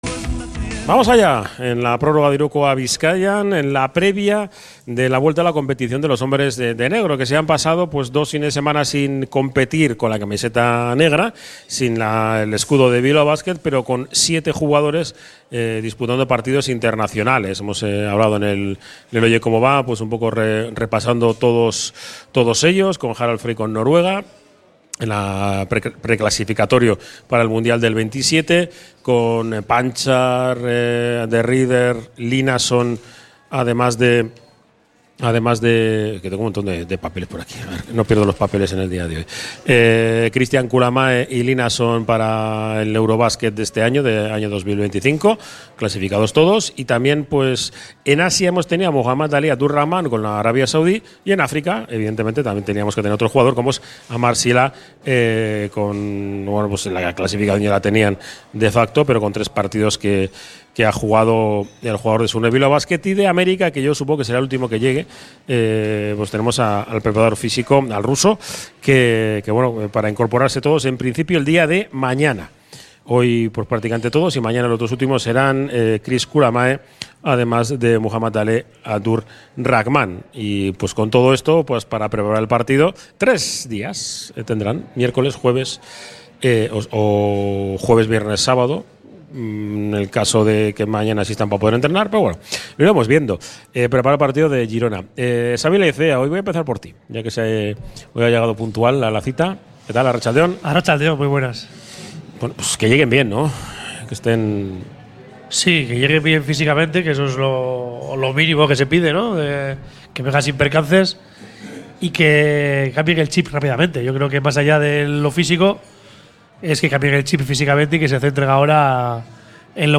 Desde el Bar Izar la Quinta Estrella